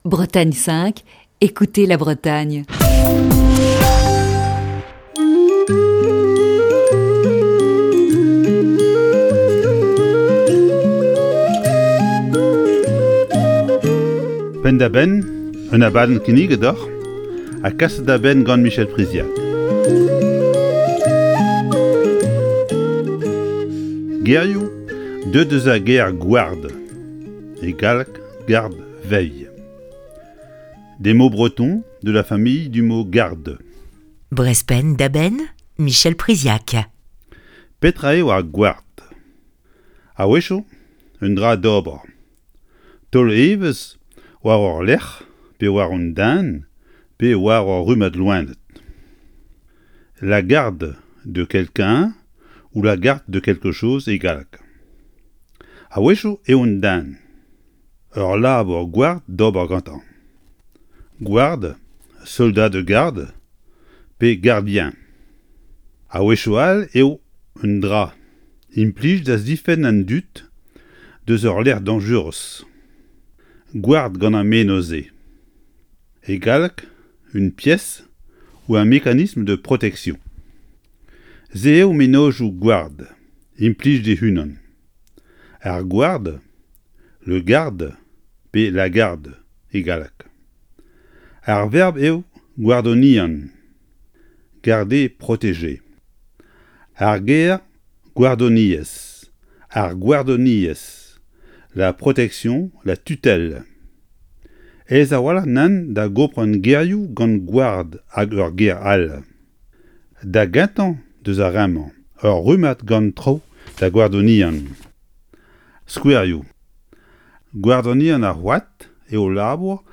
Chronique du 28 janvier 2021.